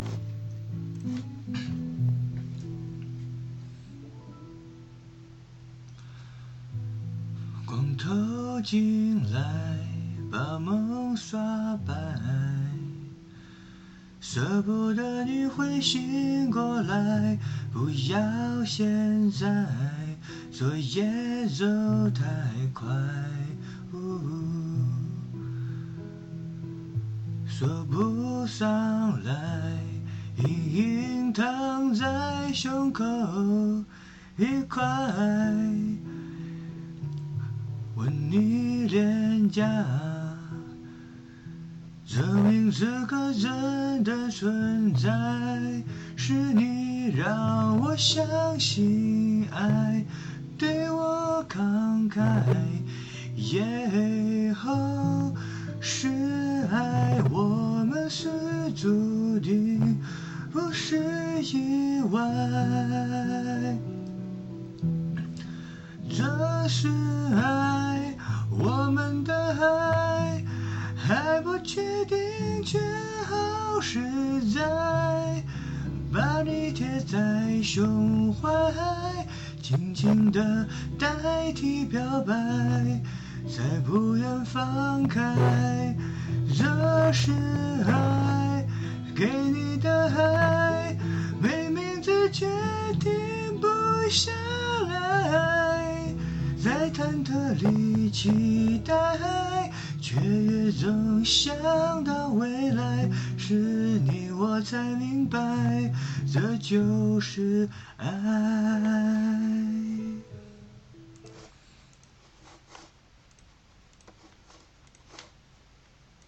这次是哪麦克风录得。。。。 效果好一点。。但是唱功不好。。。